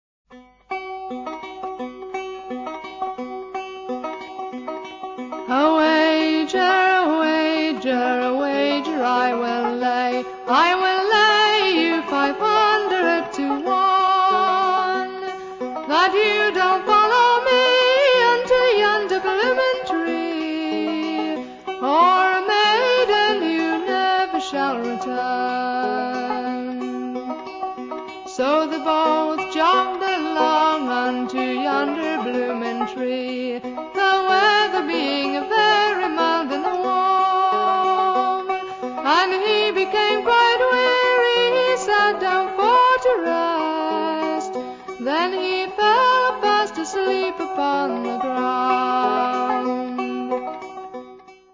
First part, 0:52 sec, mono, 22 Khz, file size: 202 Kb.